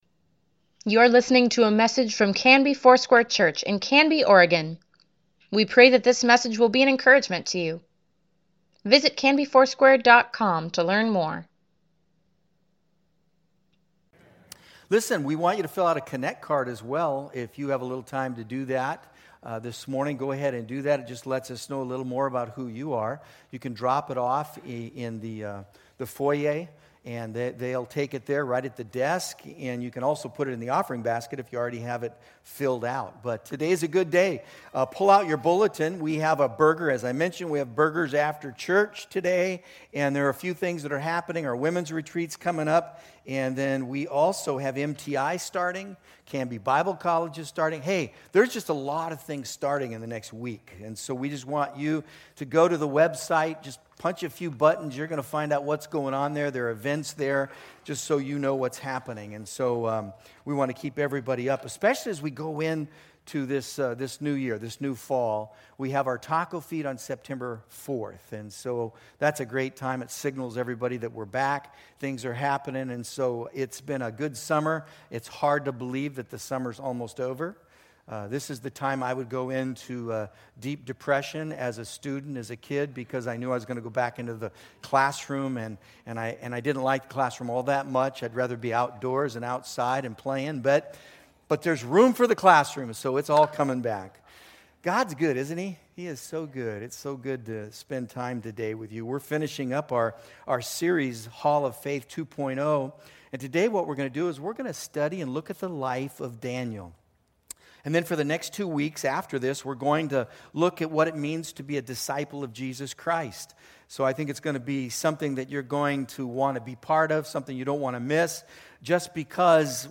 Weekly Email Water Baptism Prayer Events Sermons Give Care for Carus Daniel August 25, 2019